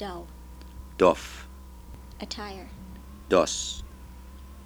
While there are more consonants than this in Dutch, these were the ones present in the word list recordings from the UCLA phonetics lab archive.